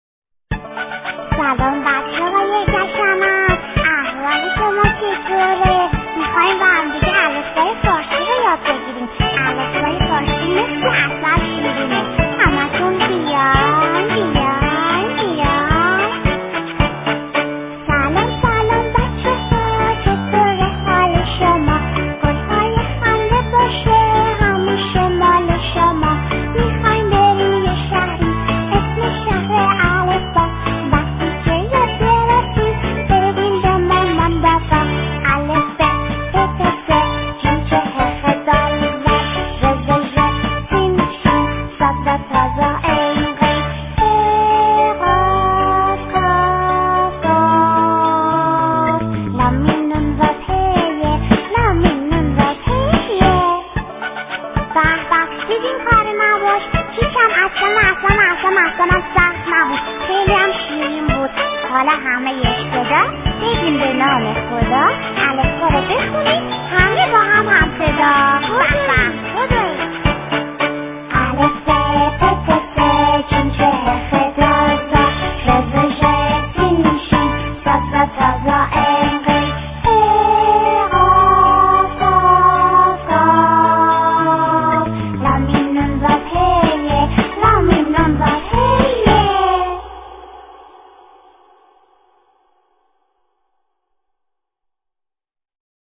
آهنگ شاد کودکانه برای جشن الفبا کلاس اولی ها